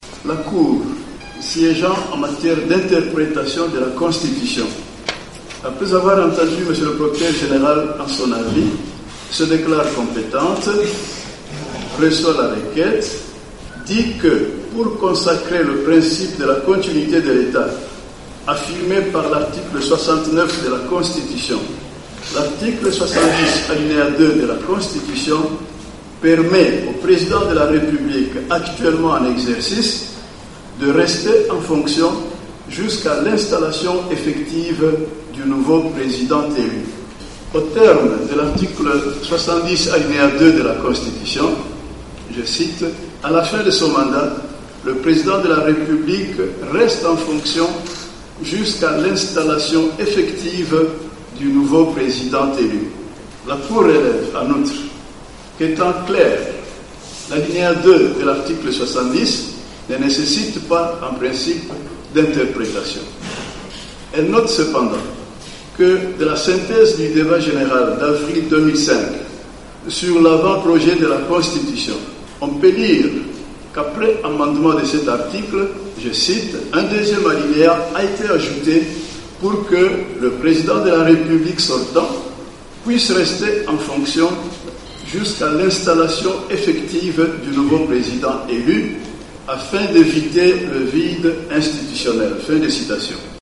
Lecture de l'arrêt par Benoît Luamba, président de la Cour constitutionnelle congolaise